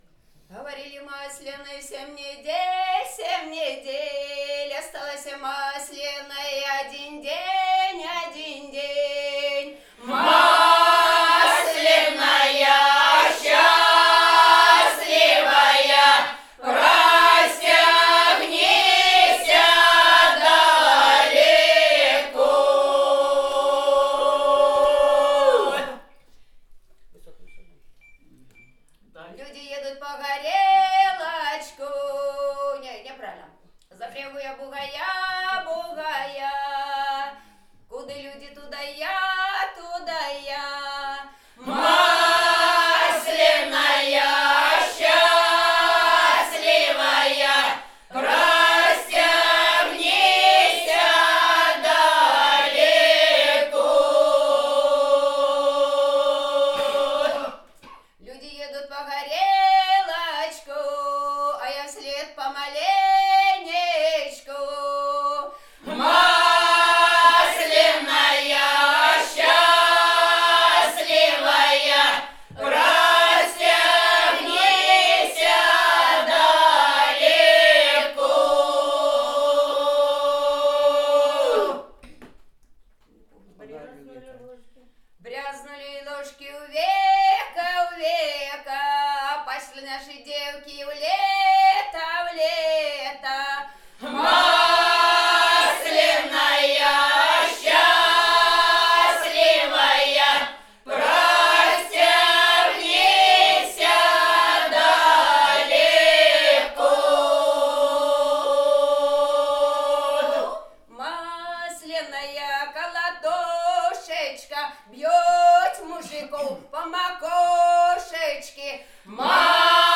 Остальные певицы поют нижнюю голосовую партию. В обрядовых песнях она звучит почти всегда в унисон, тогда как в лирических делится на две относительно самостоятельных линии.
01 Масленская песня «Говорили, масленая семь недель» в исполнении ансамбля «Калинушка» с. Старый Кривец Новозыбковского р-на Брянской обл.